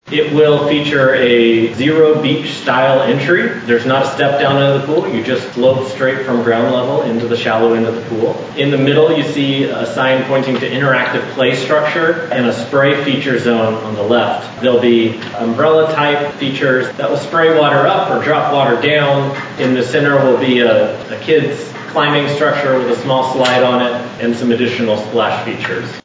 The architectural design for the Elk City Pool Complex was unveiled and approved during the latest meeting of the Elk City Commission Monday night.